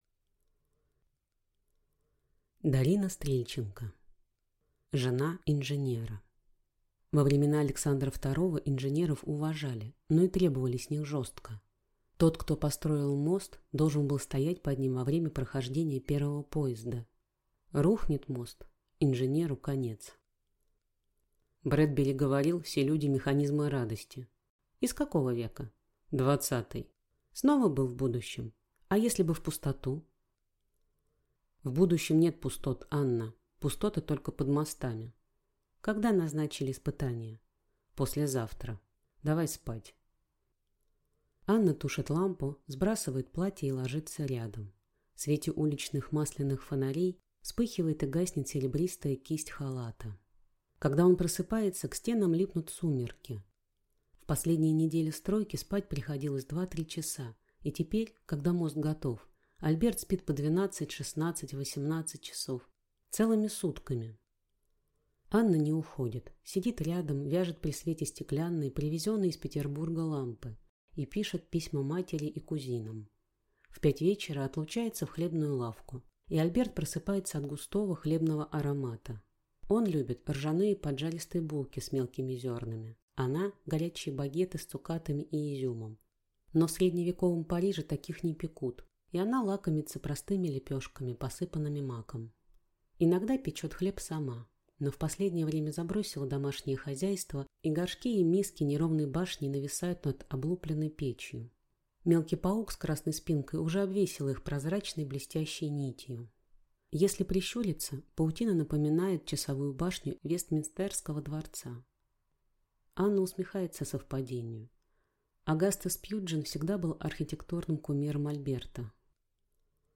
Aудиокнига Жена инженера